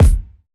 CC - Clutch Kick.wav